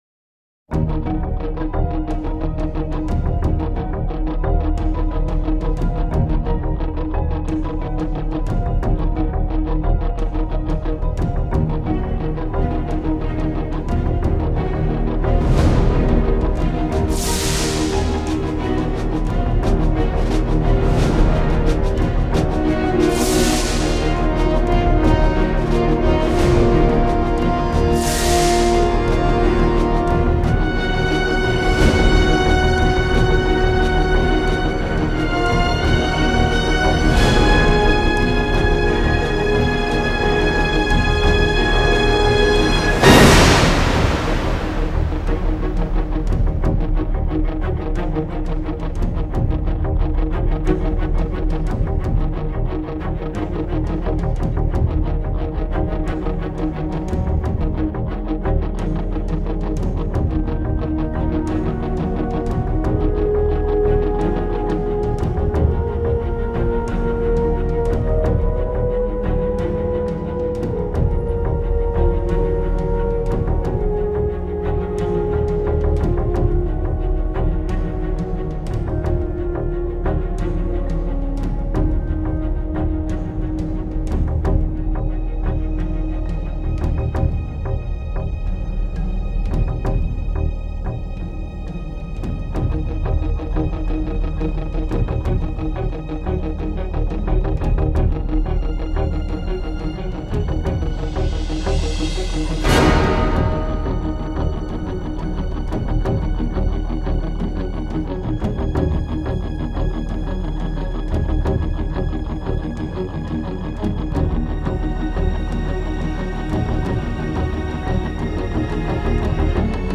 Тип:Score